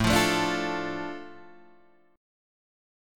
Asus4#5 chord {5 8 7 7 6 5} chord